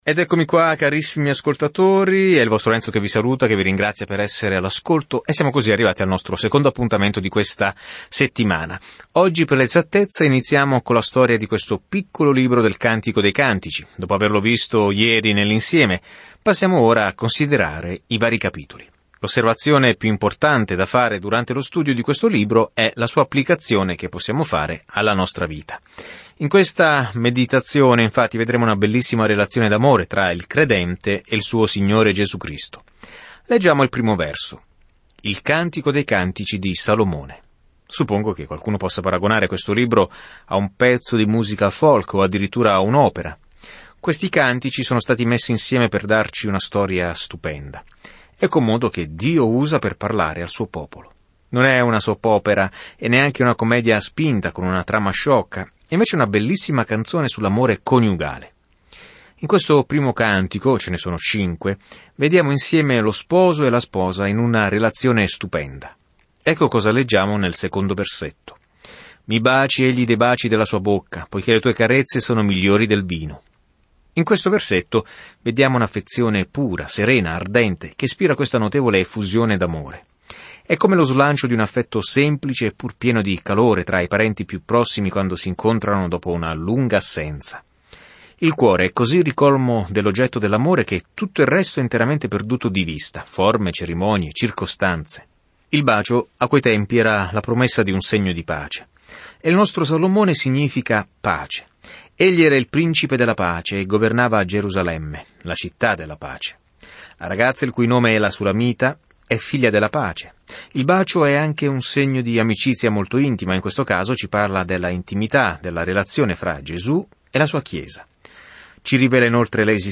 Scrittura Cantico dei Cantici 1:1-8 Giorno 1 Inizia questo Piano Giorno 3 Riguardo questo Piano I Cantici dei Cantici è una piccola canzone d'amore che celebra l'amore, il desiderio e il matrimonio con un ampio paragone con il modo in cui Dio ci ha amati per la prima volta. Viaggia ogni giorno attraverso il Cantico dei Cantici mentre ascolti lo studio audio e leggi versetti selezionati della parola di Dio.